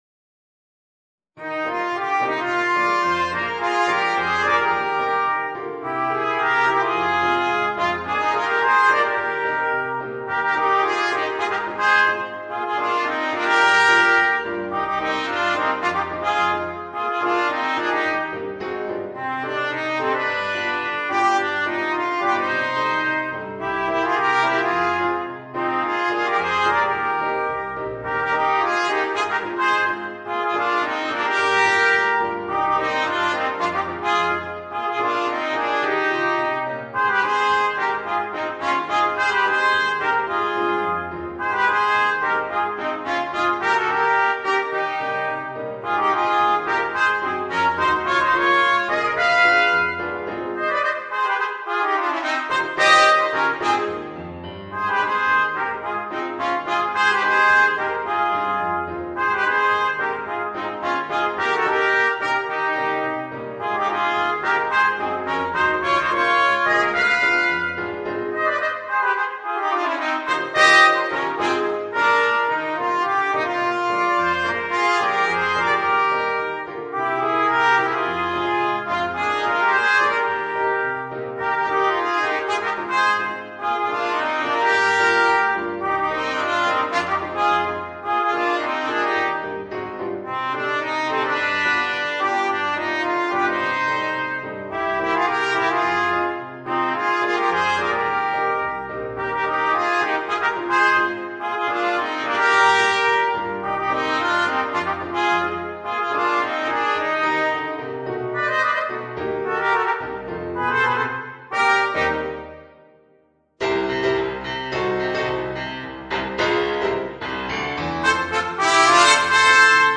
Voicing: 2 Trumpets and Piano